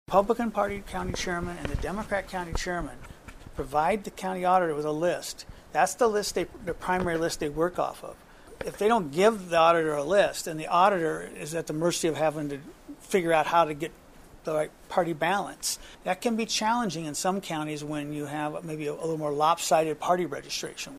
IOWA SECRETARY OF STATE PAUL PATE JOINED WOODBURY COUNTY AUDITOR PAT GILL AND STAFF FRIDAY MORNING AS THE COUNTY CONDUCTED PUBLIC TESTS ON ITS VOTING MACHINES AHEAD OF THE NOVEMBER 8TH GENERAL ELECTION.
HE SAYS MANY PEOPLE DO NOT REALIZE THAT THE WORKERS ARE SPLIT BETWEEN THE MAJOR PARTIES: